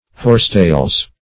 horsetails - definition of horsetails - synonyms, pronunciation, spelling from Free Dictionary